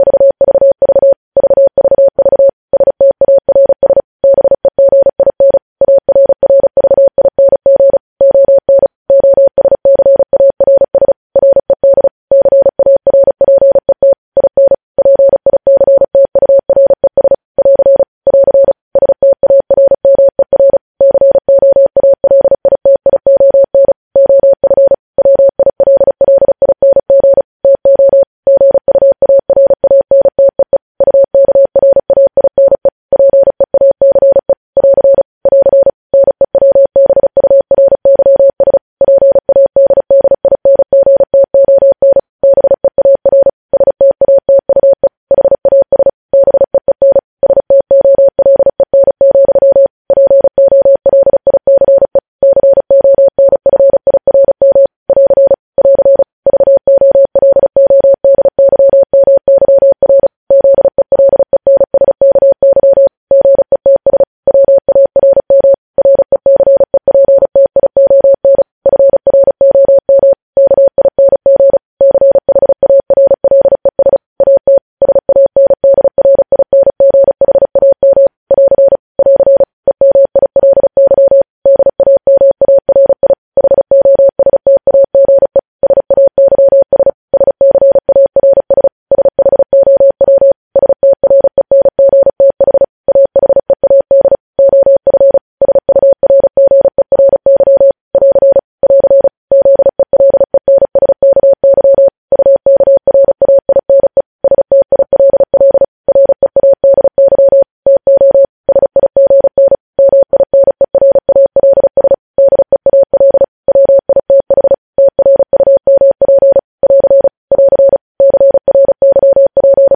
News Headlines at 35 – News Headlines in Morse Code at 35 WPM – Lyssna här